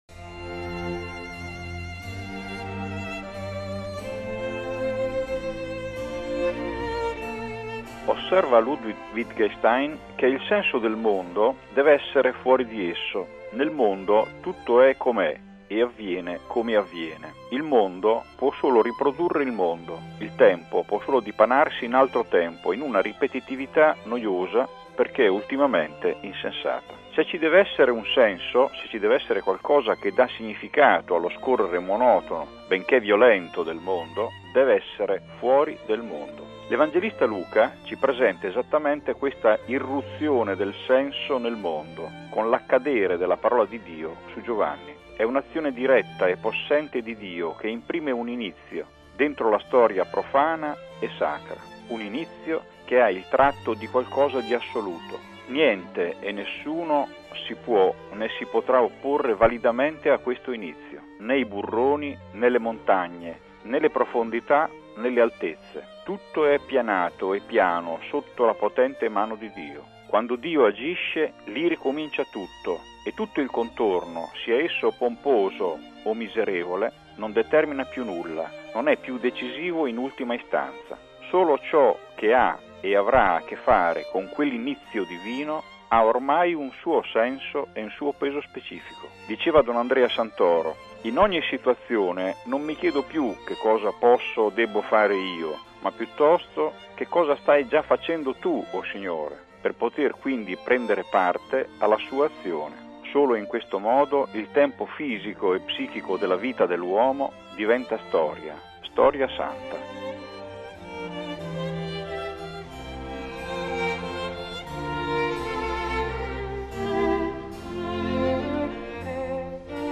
Su questo brano del Vangelo, ascoltiamo il commento del teologo